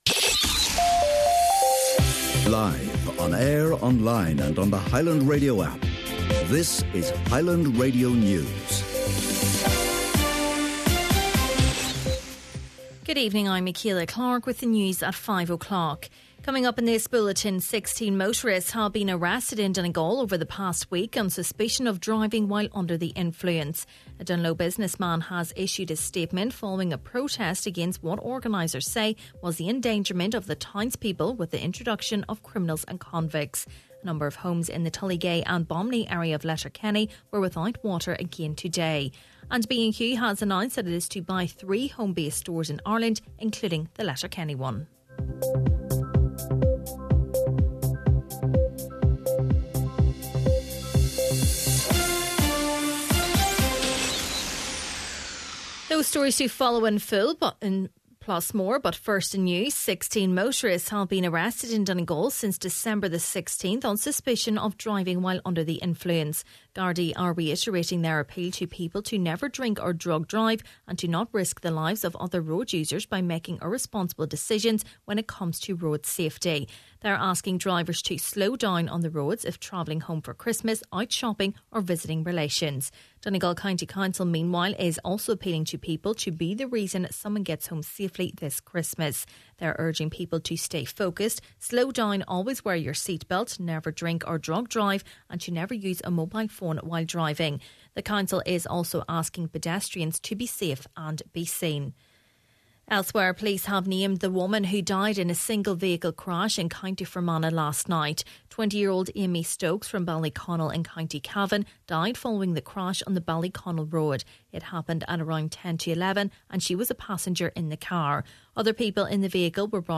Main Evening News, Sport and Obituaries – Tuesday, December 24th